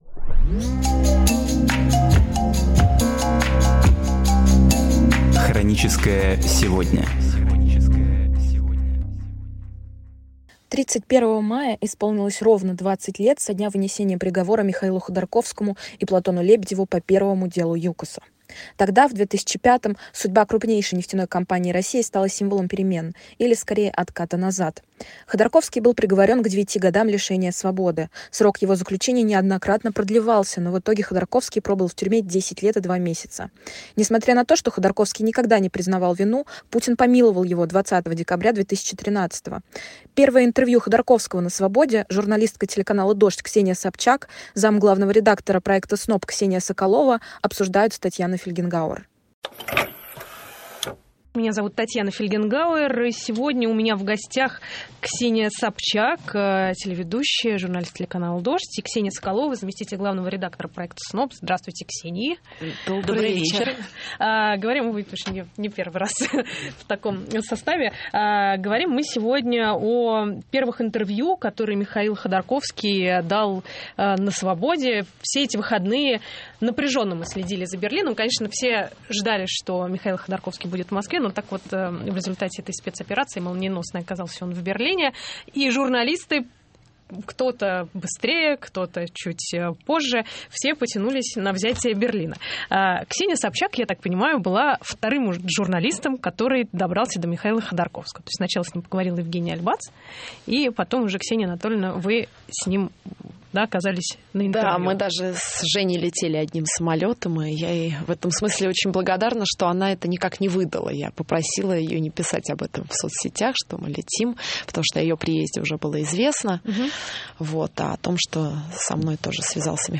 Архивные передачи «Эха Москвы» на самые важные темы дня сегодняшнего